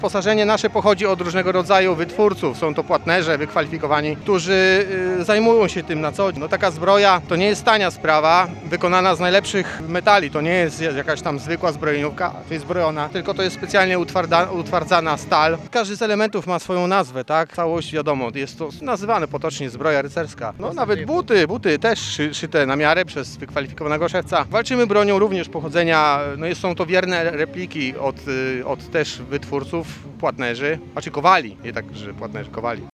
– Jesteśmy grupą składającą się z miłośników historii przeważnie żołnierzy 15 GBZ z Orzysza i Giżycka- dodaje rekonstruktor opisując swoje uzbrojenie.